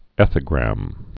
(ēthə-grăm)